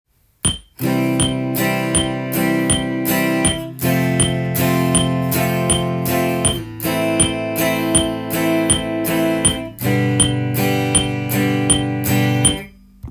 コードで裏拍練習
メトロノームを鳴らしながら画像のリズムを弾くとわかりやすく
メトロノームにコードストロークした時　合わないように
そうすることで　８分音符がタイでくっついて裏のリズムが完成します。